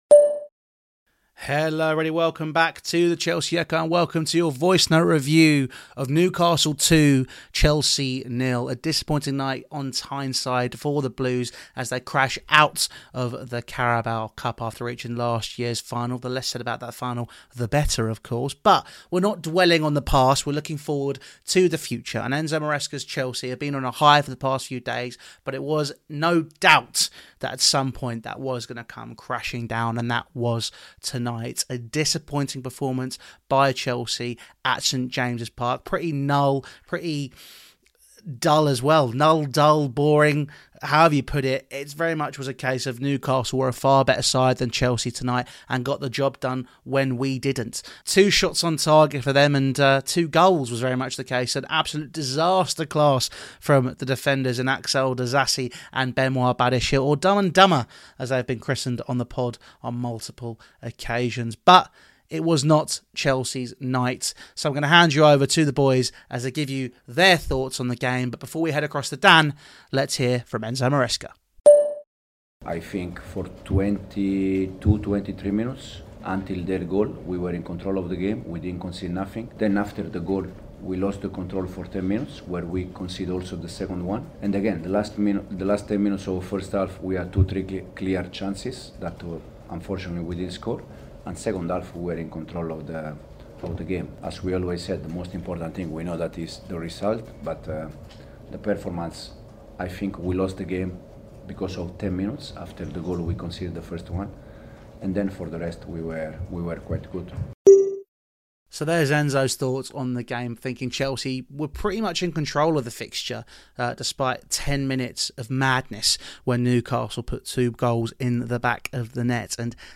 Newcastle wanted it more! | Newcastle 2-0 Chelsea | Voicenote Review